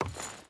Wood Chain Walk 2.wav